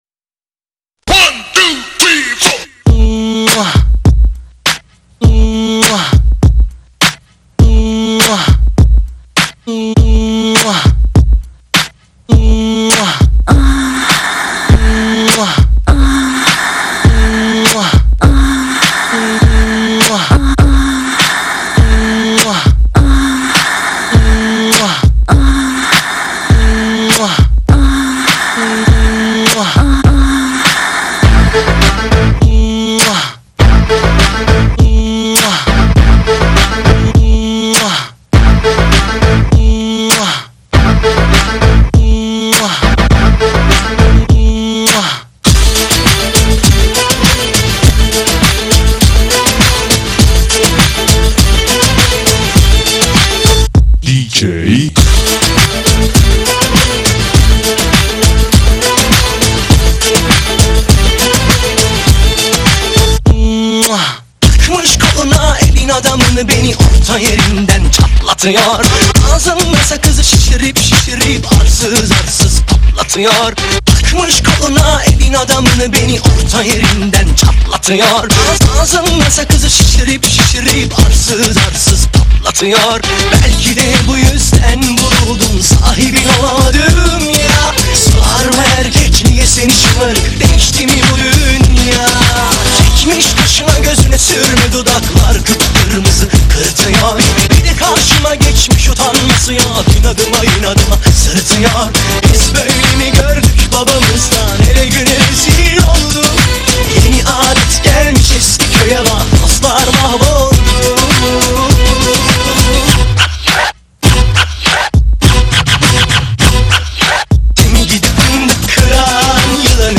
喜欢超强节奏感的请进~